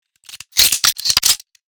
Download Free Sci-Fi Sound Effects | Gfx Sounds
Sci-fi-weapon-reload-rifle-reloading-4.mp3